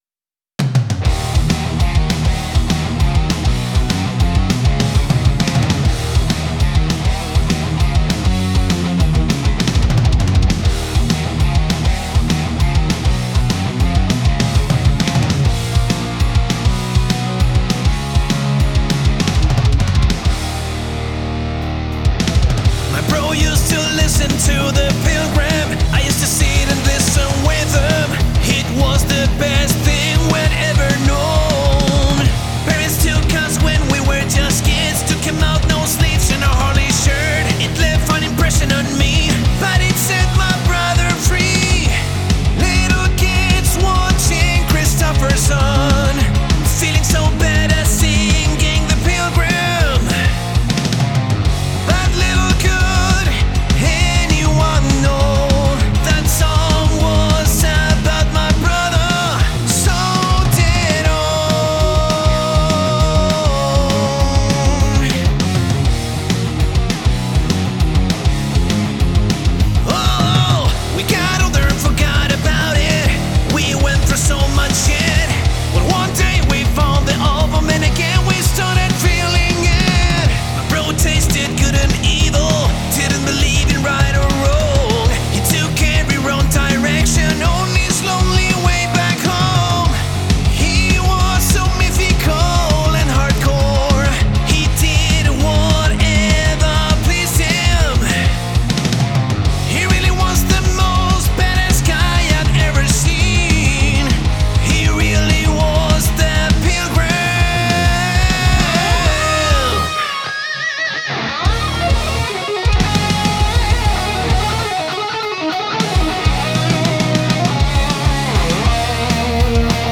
But it's cool there is a glam metal version of it 👍 🙂